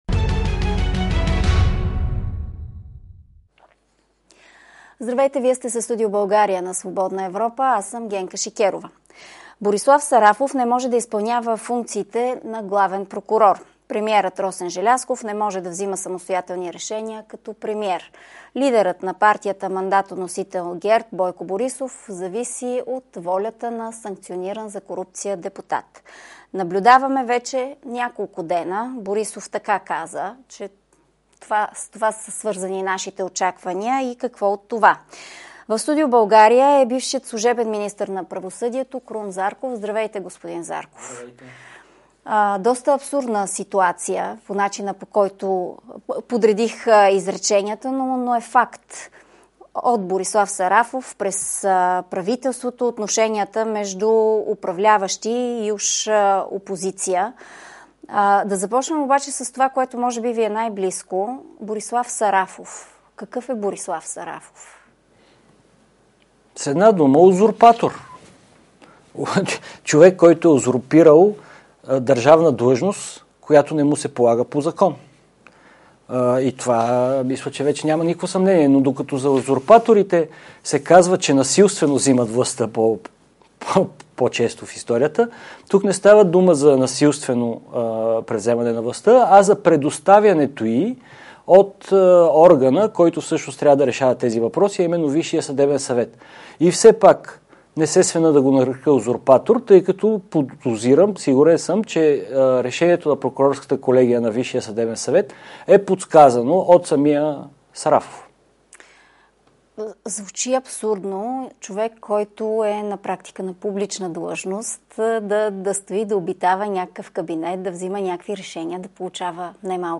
Ще има ли преформатиране на властта? В Студио България е бившият служебен министър на правосъдието Крум Зарков.